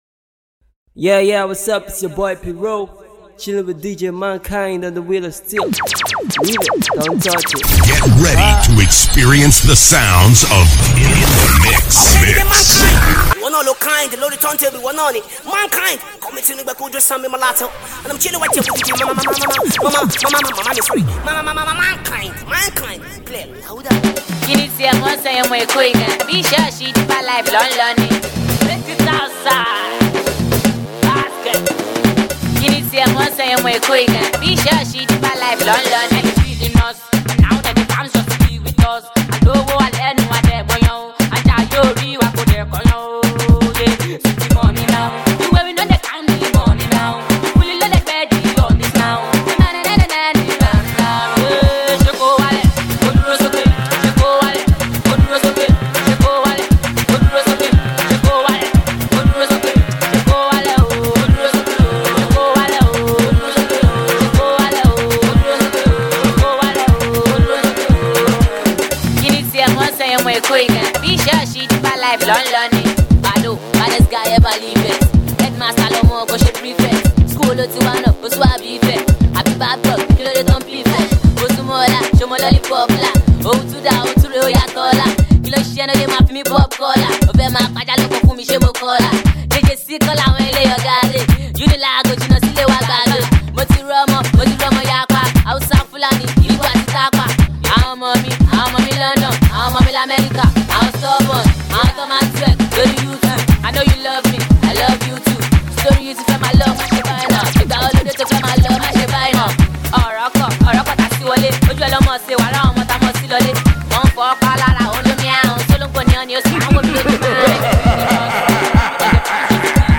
mixtape